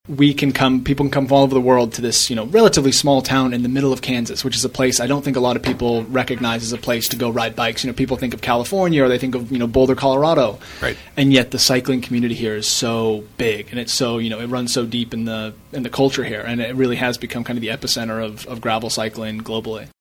‘This is the Super Bowl of gravel:’ Past Unbound champions reflect on experiences during KVOE Morning Show interview